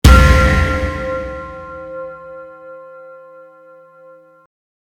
BELL BELL DRUM DARK HIT
Ambient sound effects
bell_-_Bell_drum_-_dark_hit_.mp3